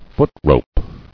[foot·rope]